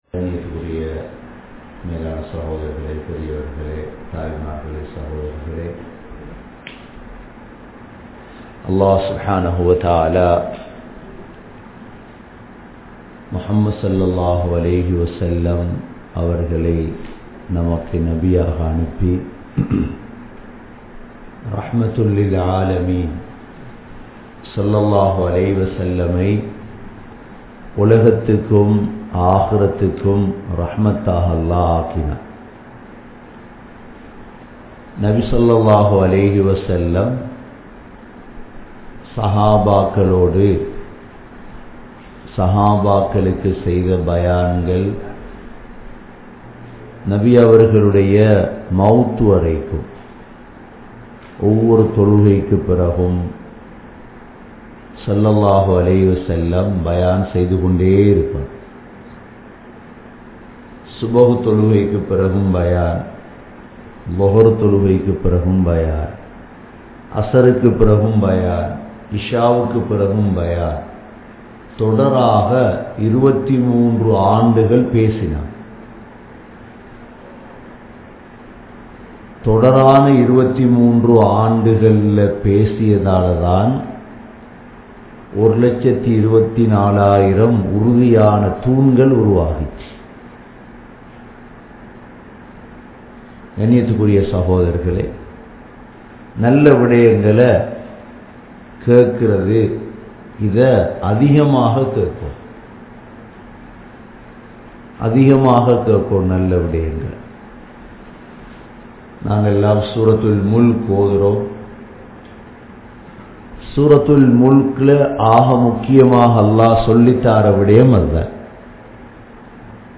Shahabaakkalin Sirappuhal (ஸஹாபாக்களின் சிறப்புகள்) | Audio Bayans | All Ceylon Muslim Youth Community | Addalaichenai
Canada, Toronto, Thaqwa Masjidh